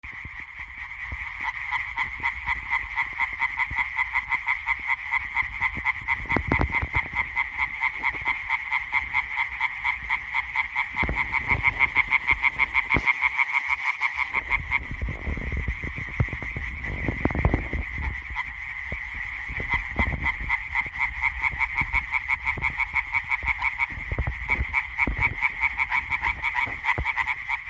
Common tree frog, Hyla arborea
Ziņotāja saglabāts vietas nosaukumsdīķis
Count10 - 20
Ļoti daudz un skaļas.